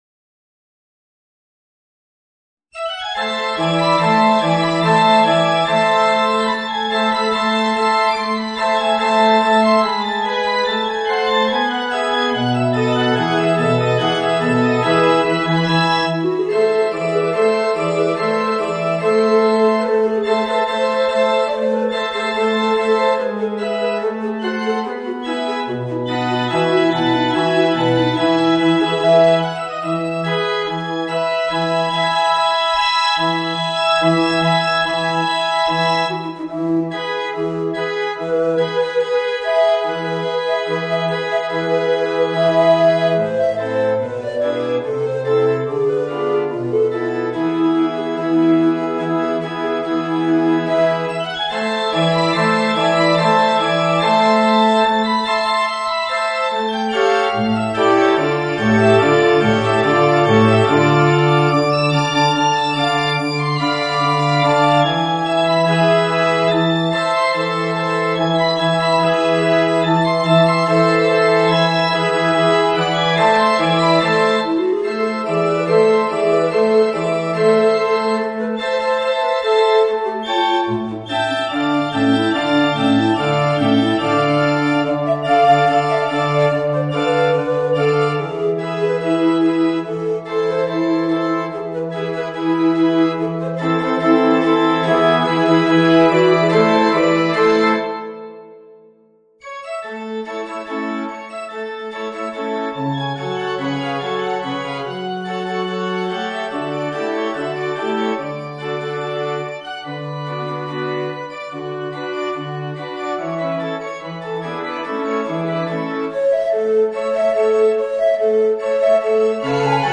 Voicing: Tenor Recorder and Organ